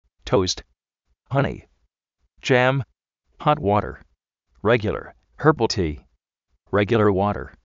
tóust
jáni
yám
ját uórer
réguiular, jérbal tí: